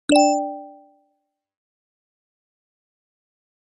Звуки уведомлений о сообщениях
Звук оповещения для сообщений в мессенджере